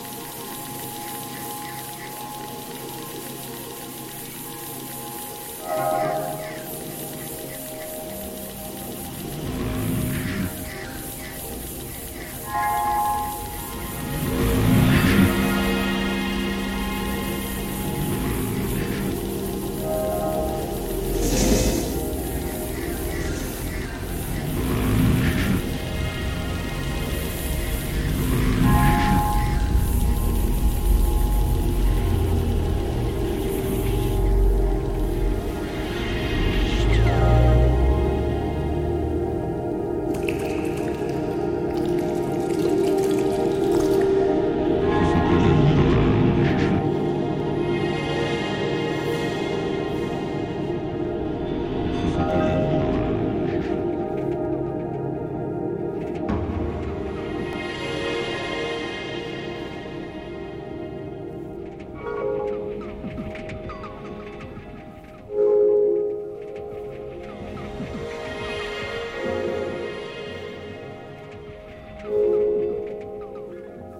plasmic ambient entertainment systems
Electronix Ambient